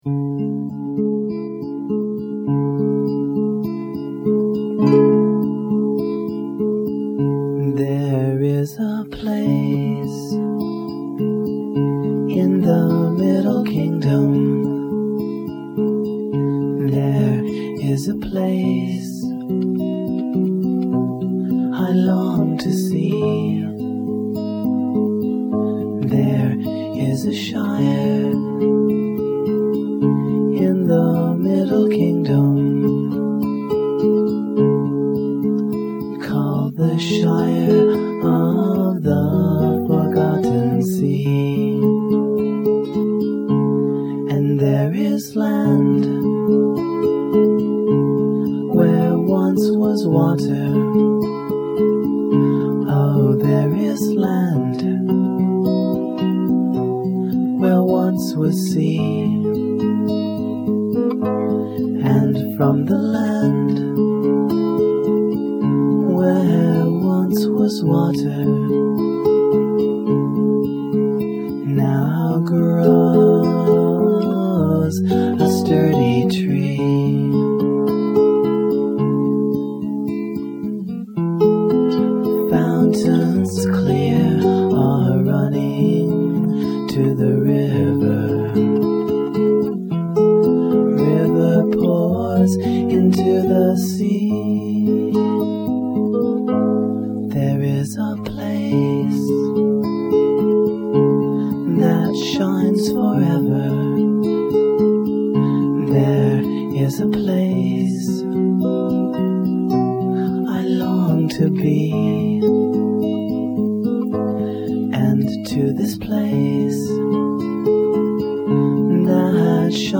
Technical Notes: The sound quality is not the best, since I was working from a 23-year-old cassette tape source which had badly deteriorated. I was able to do some digital "restoration" but that process introduced some phasing anomalies, which were enhanced by the MP3 encoding. Still, it sounds a LOT better than the tape!